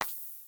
heartbeat.wav